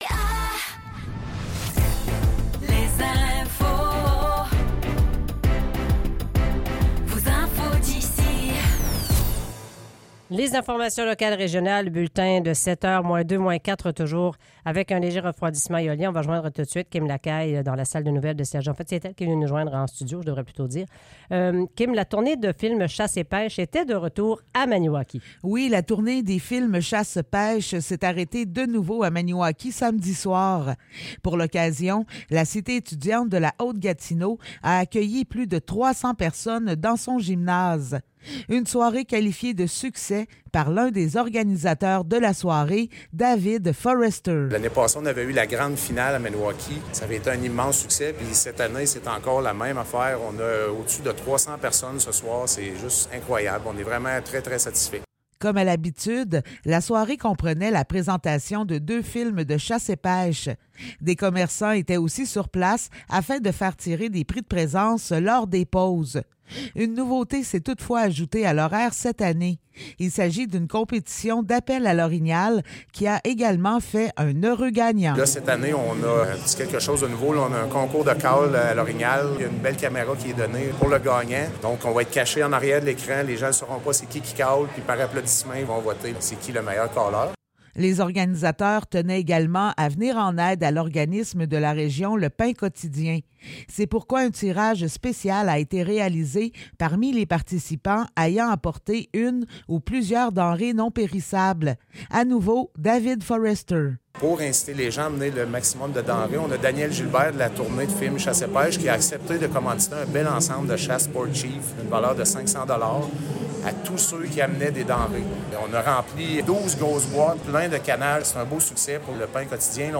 Nouvelles locales - 12 février 2024 - 7 h